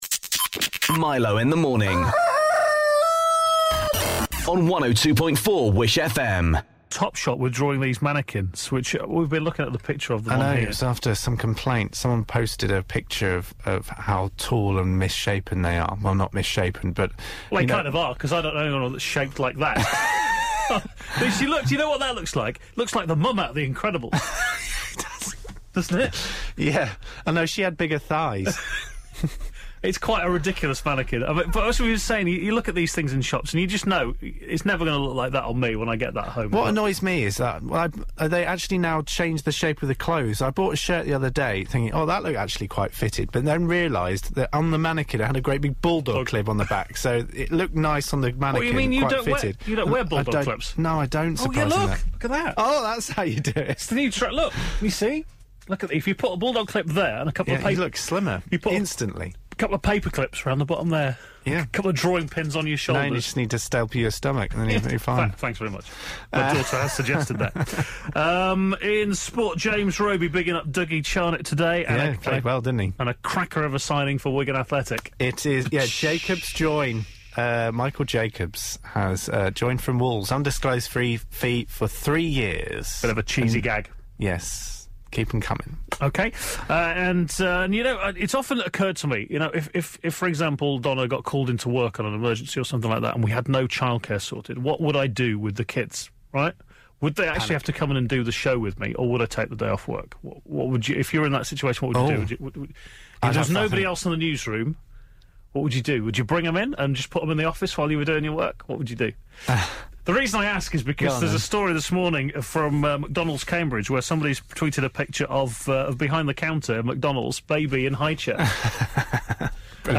This morning 'fashion' chat got us giggling